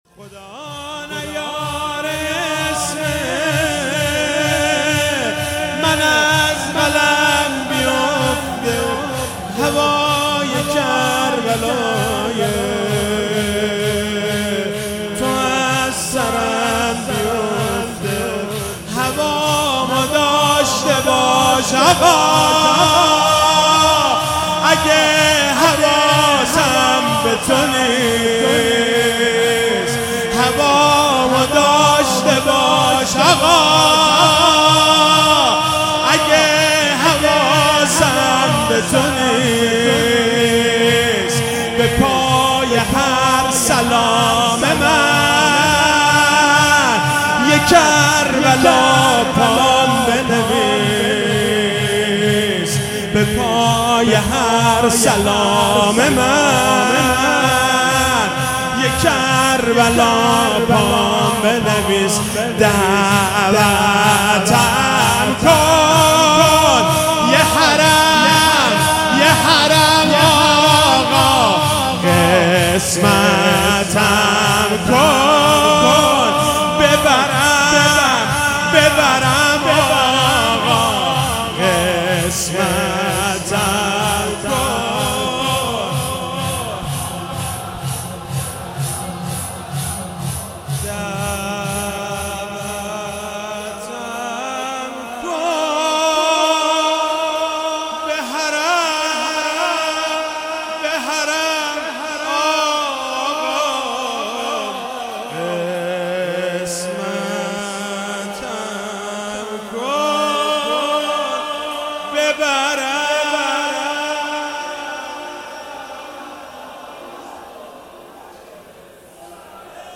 شب 19 رمضان97 - شور - خدا نیاره اسم من از قلم بیفته